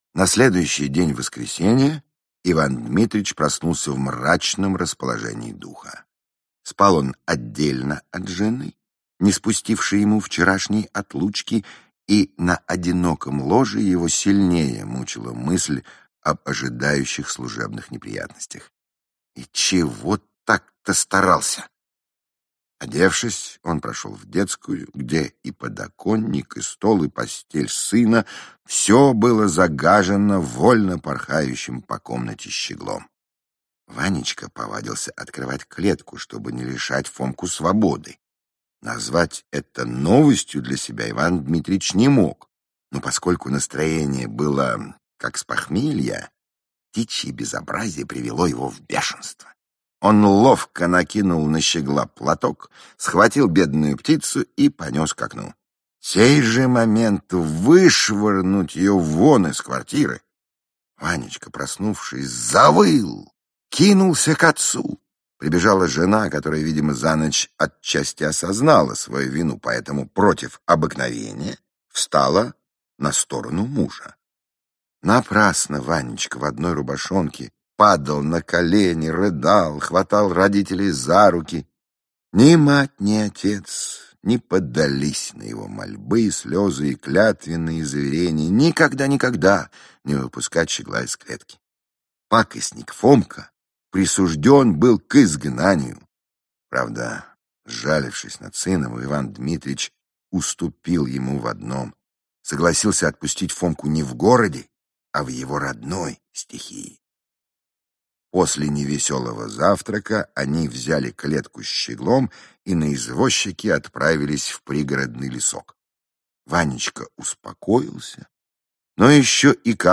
Аудиокнига Дом свиданий | Библиотека аудиокниг